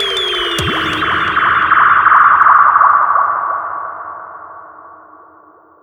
FILL FX 01-L.wav